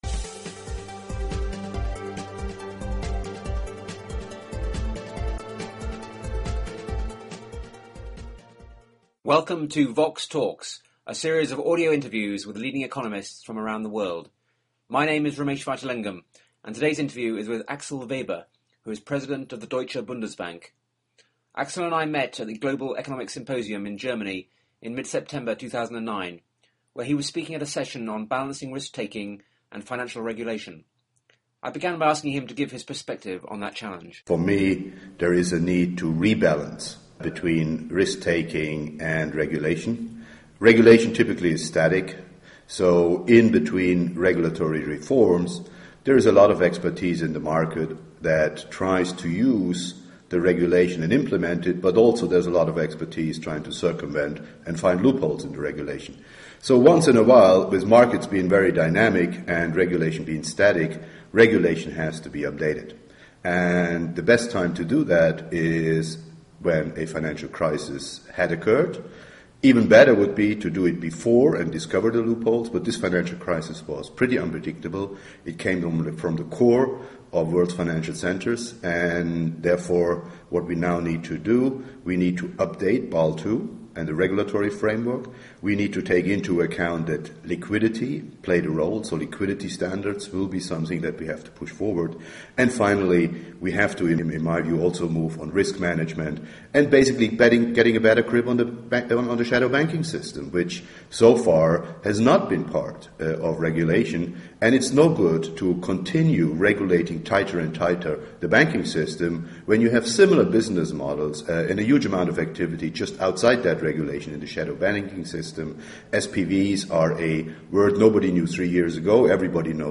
They discuss the opportunity for central bankers and other G20 players to design and implement new regulation, and the likely future size of the financial sector in the overall economy. The interview was recorded at the Global Economic Symposium in Schleswig-Holstein in September 2009.